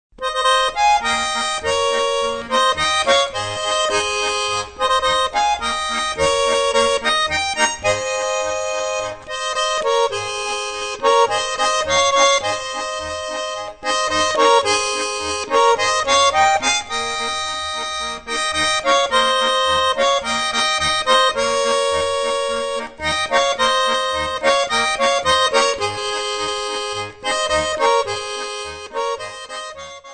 Stücke der originalen Volksmusik berücksichtigt.